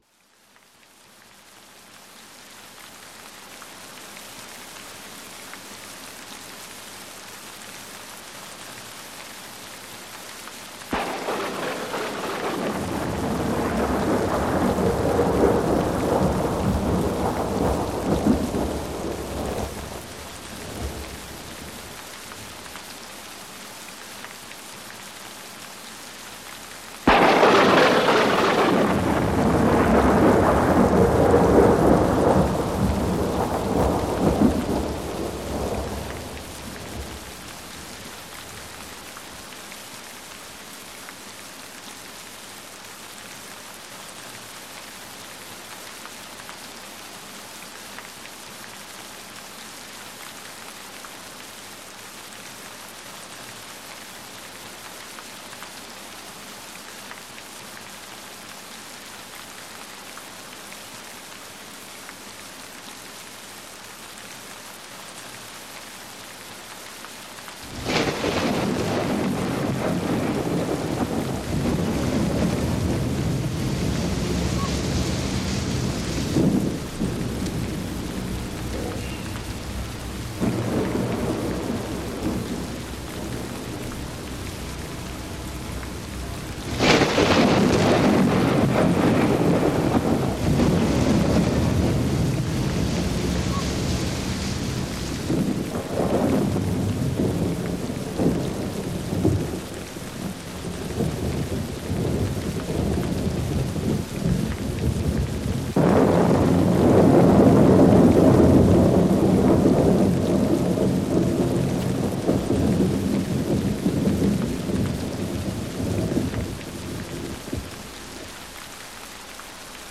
Groza.mp3